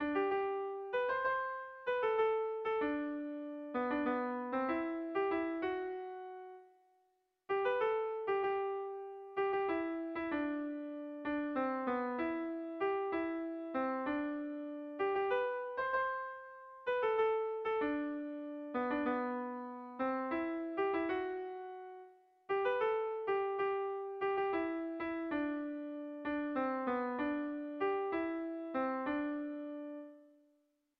Kontakizunezkoa
Zortziko handia (hg) / Lau puntuko handia (ip)
ABA2B